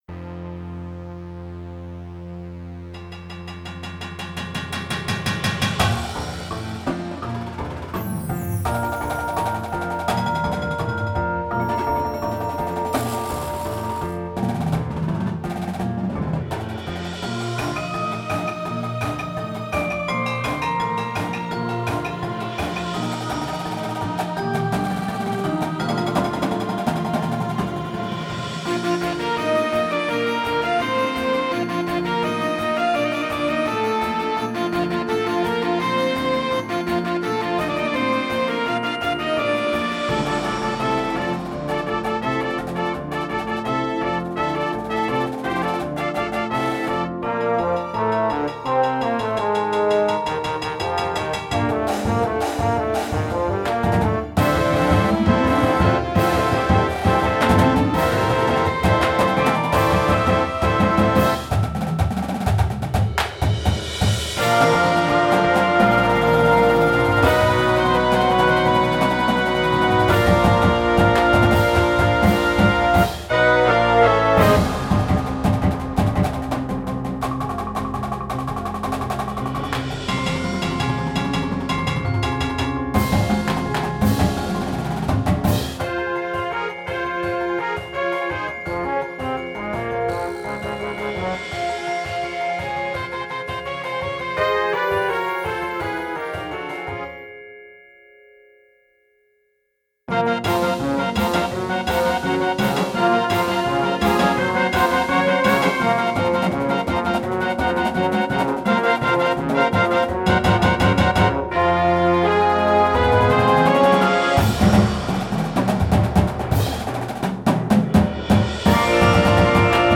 Instrumentation: Winds and Full Percussion